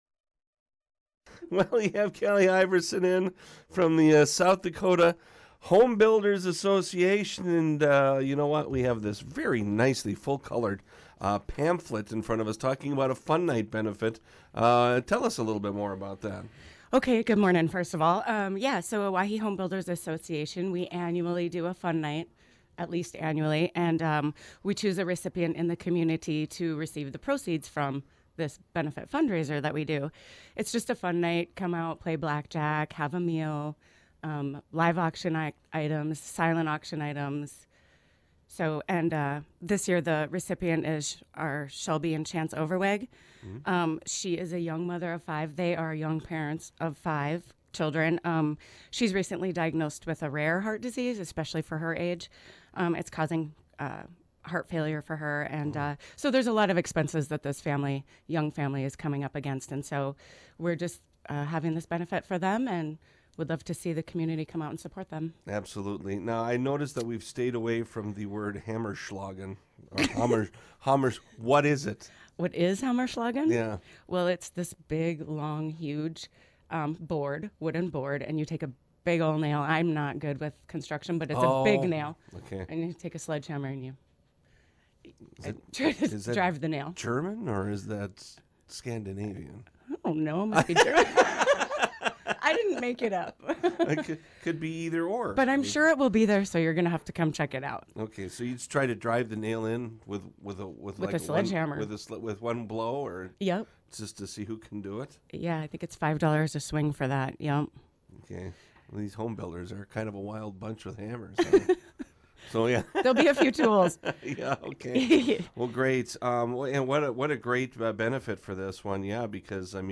During this People in Your Neighborhood segment on KGFX